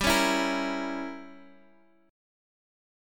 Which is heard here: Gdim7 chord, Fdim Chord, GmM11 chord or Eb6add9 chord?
Gdim7 chord